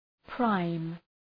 Προφορά
{praım}